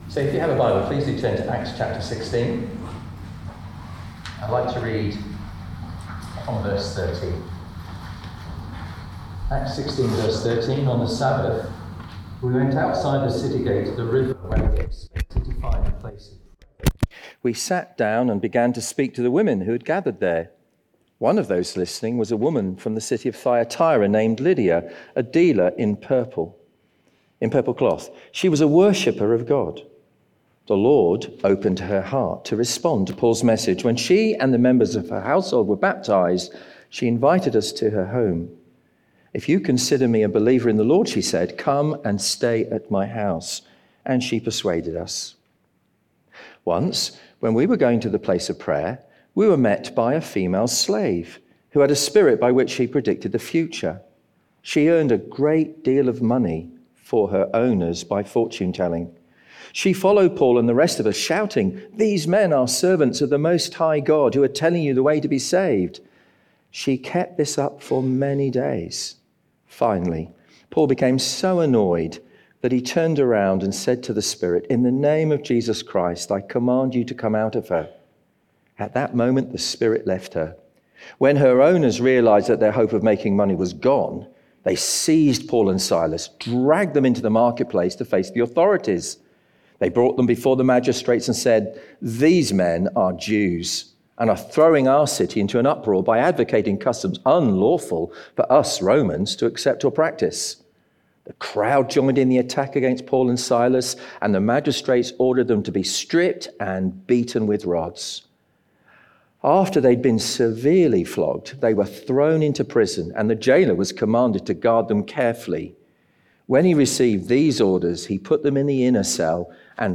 Series: Leaders' Conference 2025